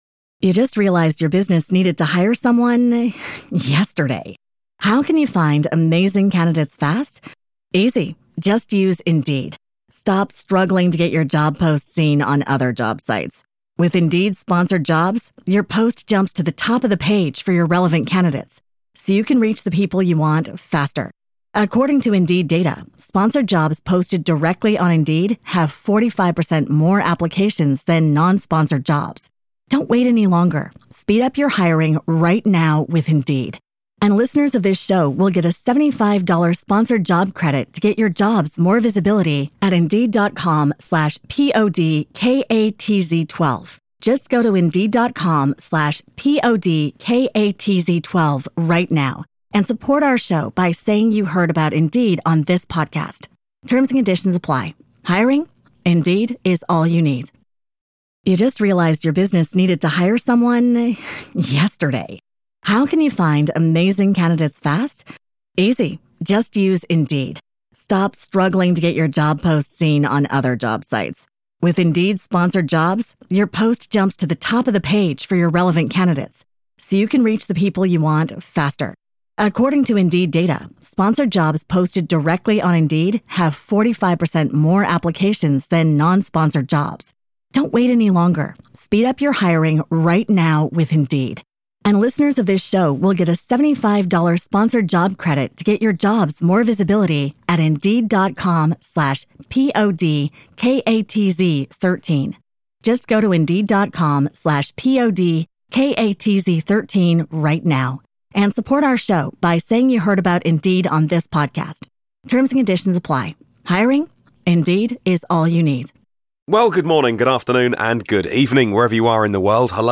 25 August 2018 | Interview: Classic VW Camper EV Conversions